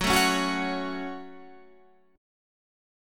F# Minor
F#m chord {2 4 4 2 2 2} chord